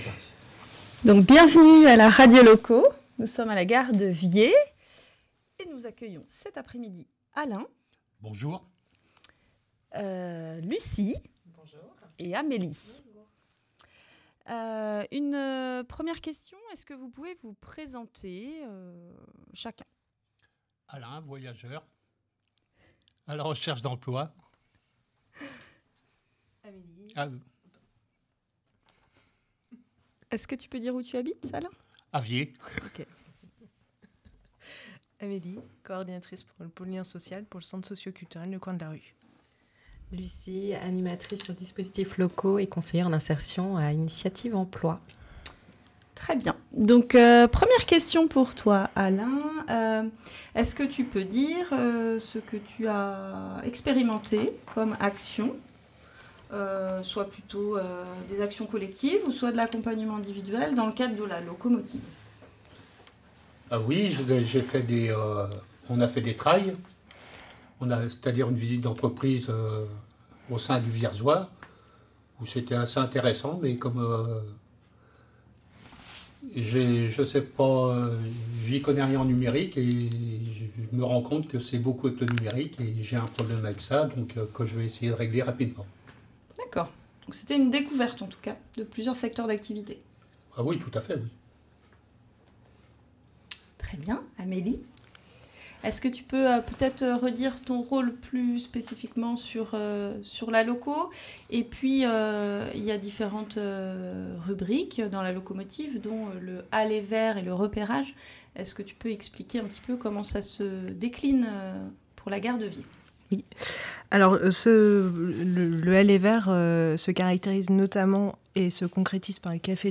Retrouvez ici l'émission Radio Loco enregistrée à la gare de Vihiers en novembre 2025 !